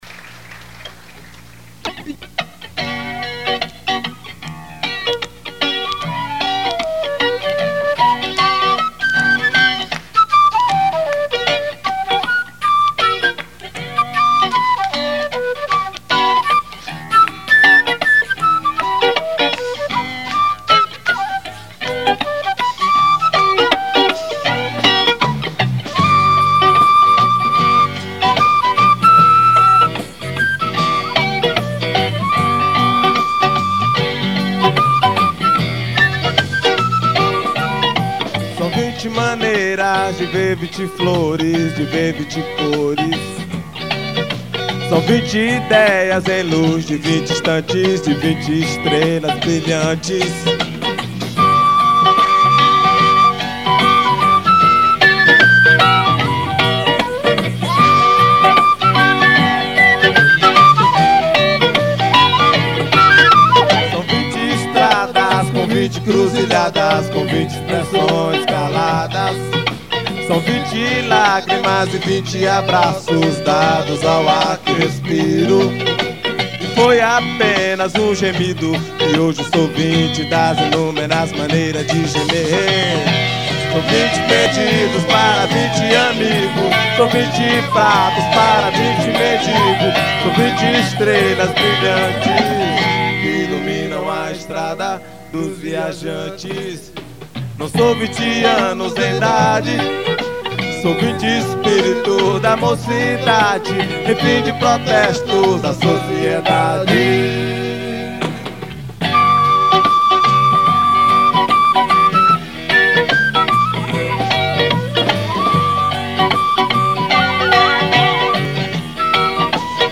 974   06:05:00   Faixa:     Rock Nacional